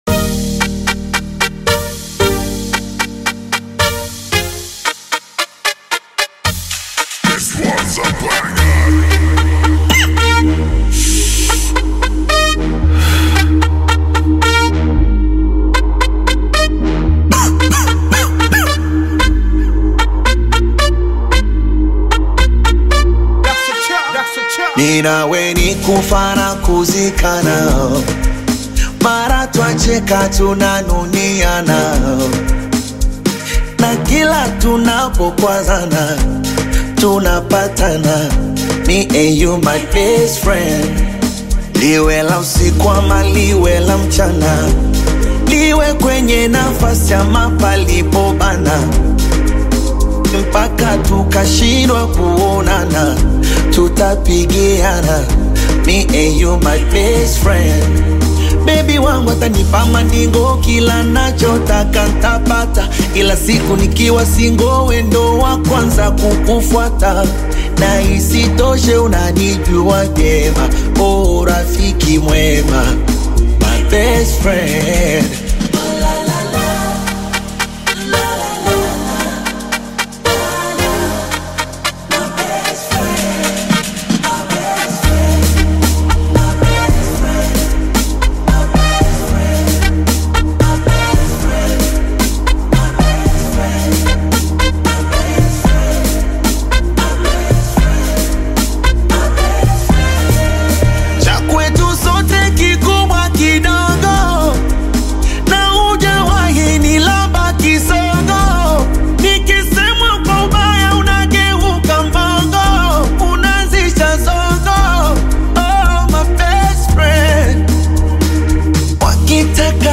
A multi-talented Tanzanian Bongo Flava performer
catchy hit